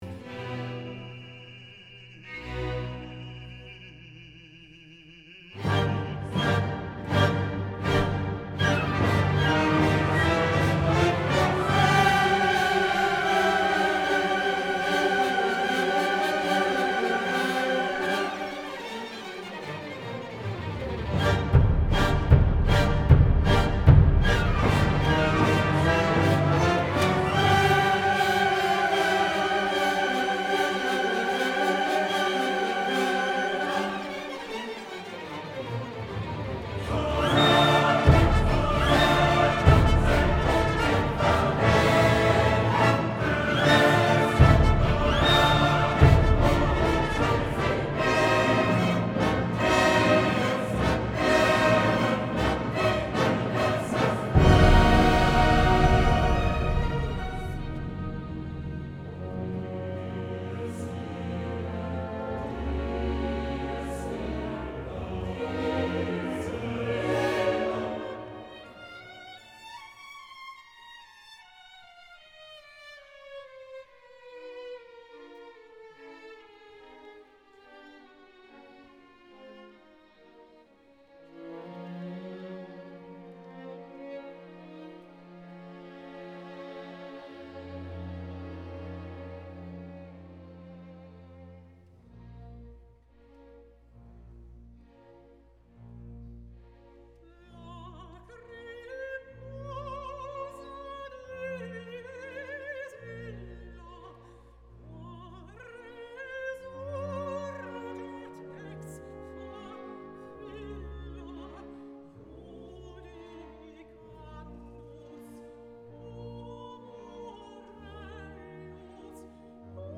Grundsätzlich habe ich möglichst breite, lebendige Hallen mit einer mittleren Decay Zeit von ca. 7 Sekunden gesucht oder gebaut. Loslegen tue ich mal mit dem Synthpattern und dem langen Hall.